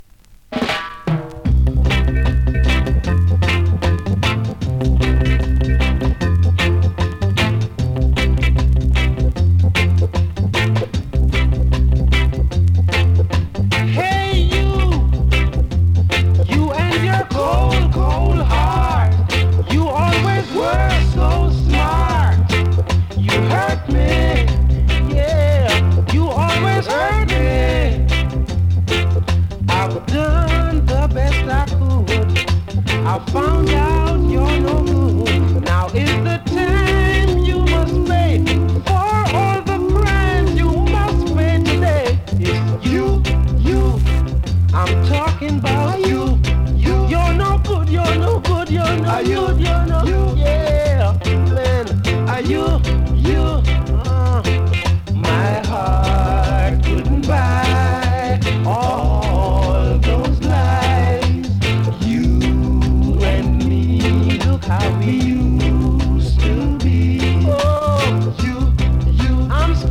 2025!! NEW IN!SKA〜REGGAE
スリキズ、ノイズかなり少なめの